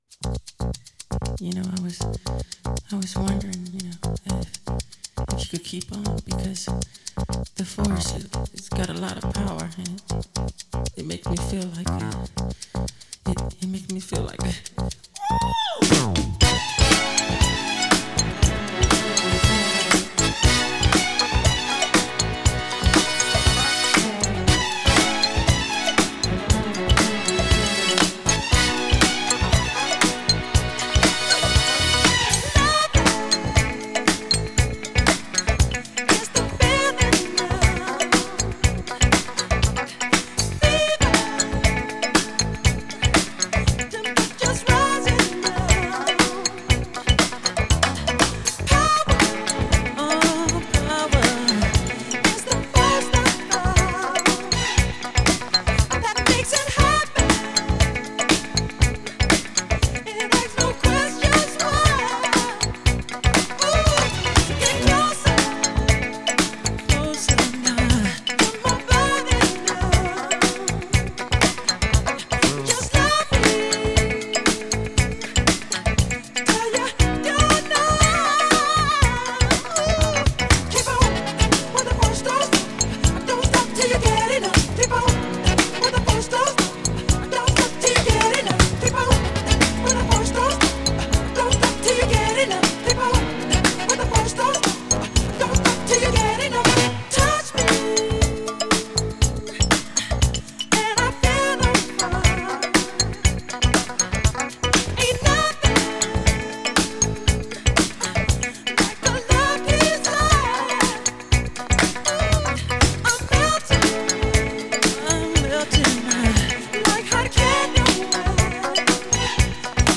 Pop, Disco, Funk, R&B, Soul